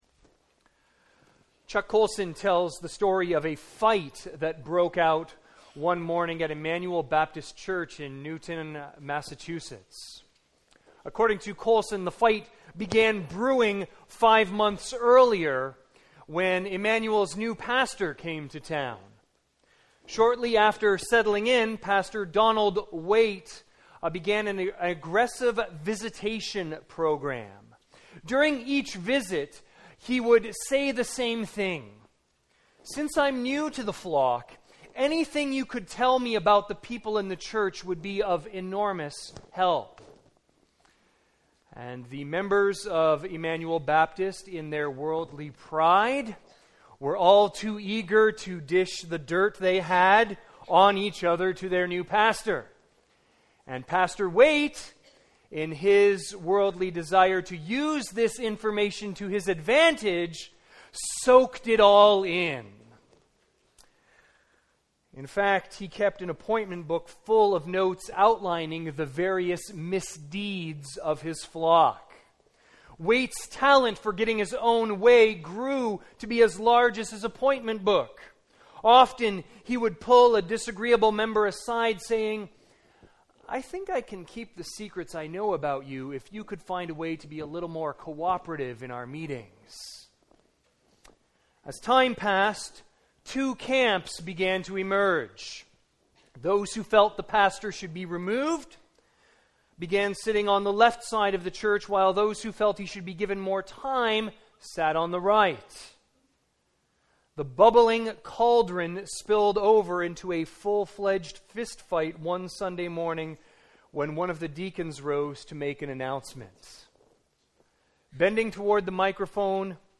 Sermons | Campbell Baptist Church
View the Sunday service.